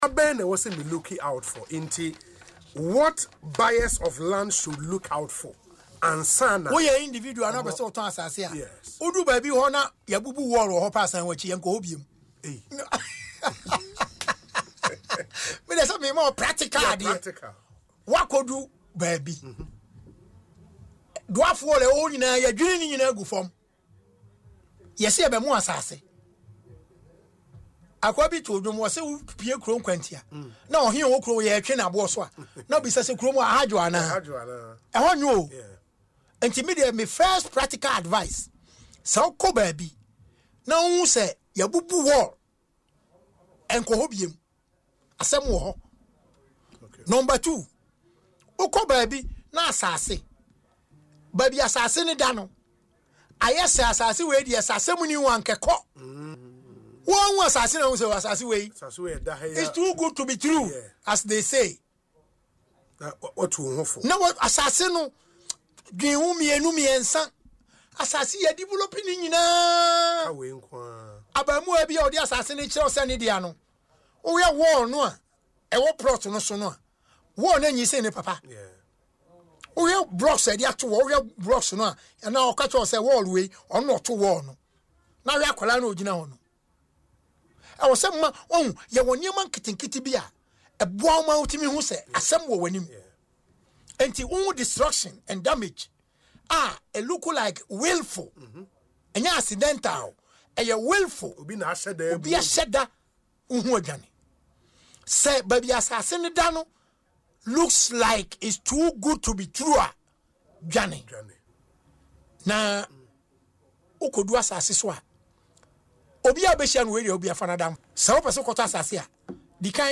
Speaking in an interview on Asempa FM’s Ekosii Sen show, he disclosed several crucial points that can help buyers avoid costly mistakes.